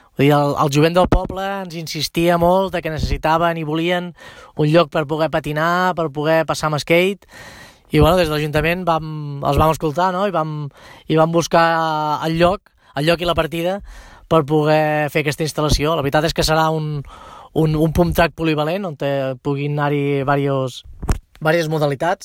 L’alcalde de Corçà, Raül Yruela, ha explicat a Ràdio Capital que està molt ‘satisfet’ que s’hagi dut a terme aquesta iniciativa popular.
alcalde-corca_final.mp3